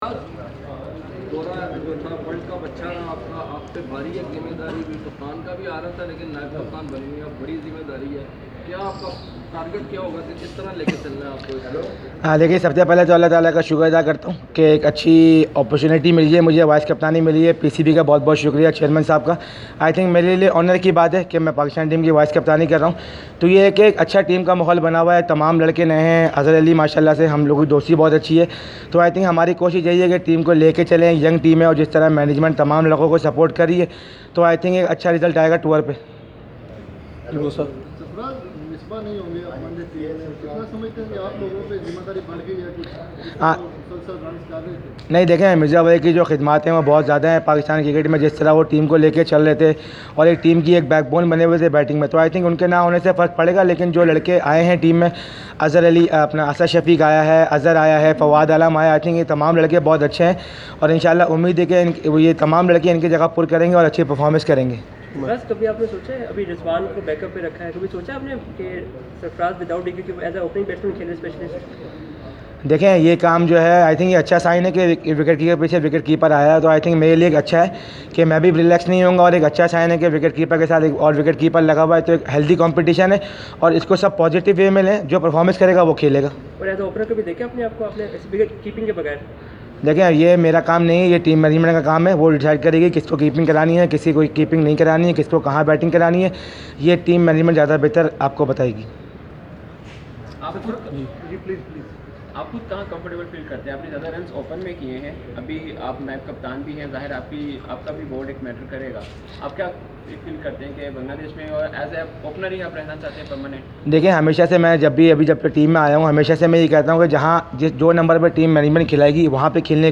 Media Talk: Sarfraz Ahmed at Gaddafi Stadium, Lahore (Audio)